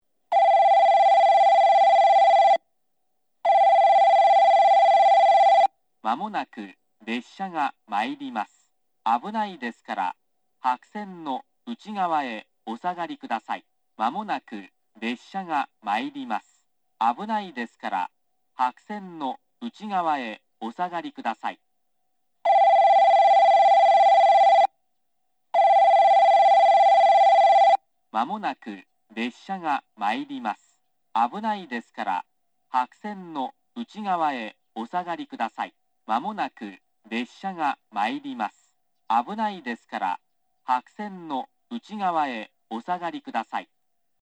接近放送　男声 現在は九州カンノ型Cとなっています。ベル2回→放送2回の流れ方をするのが特徴で、上下とも男声となっています。
スピーカーはTOA円型ワイドホーンが新規に取り付けらています。